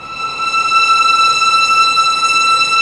Index of /90_sSampleCDs/Roland L-CD702/VOL-1/STR_Vlns 6 mf-f/STR_Vls6 mf slo